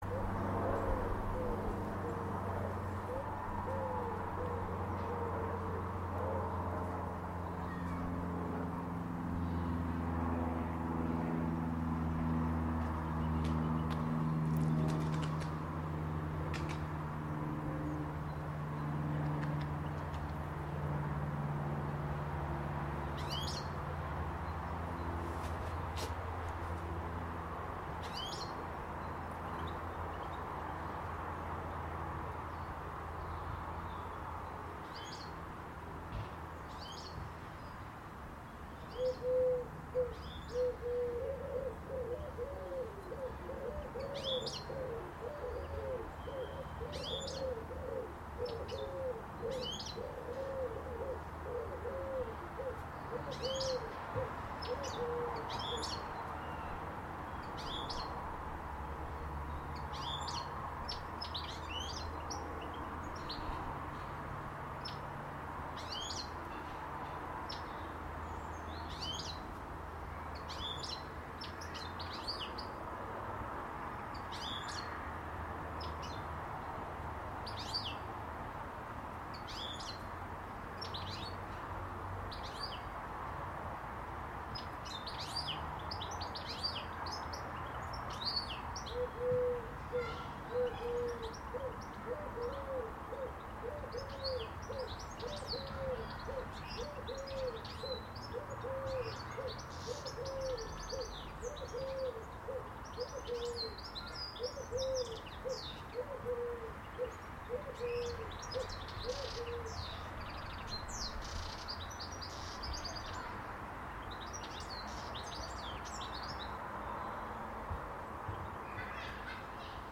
More Spring birdsong recorded on 9 March